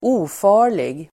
Uttal: [²'o:fa:r_lig]